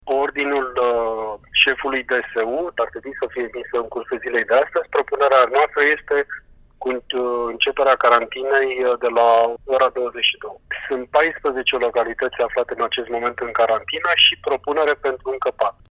Prefectul județului Ilfov spune la Europa FM că a cerut Comitetului pentru Situații de Urgență să aprobe intrarea în carantină a localităților Chitila, Măgurele, Ciorogârla și Corbeanca, după ce indicele de infectare cu SARS-C0V-2 a trecut de pragul a 6 cazuri la mia de locuitori.